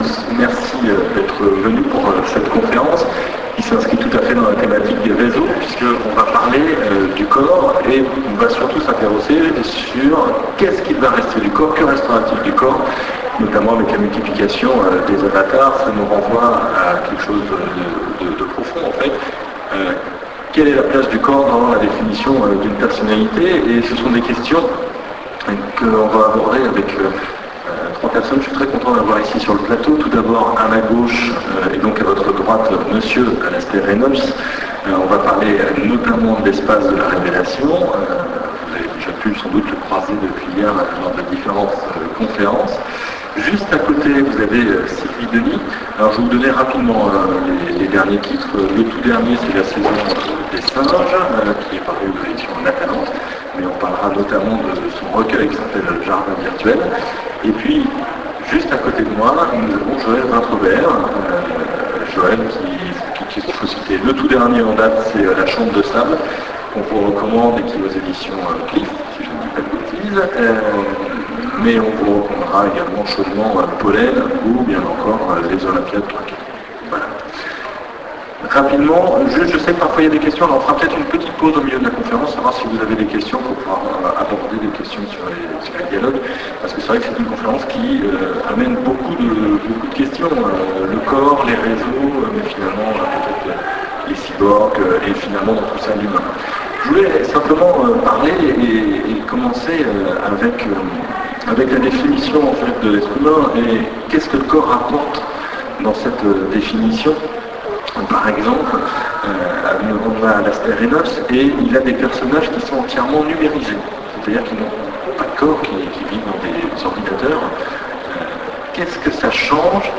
Utopiales 2008 - Nantes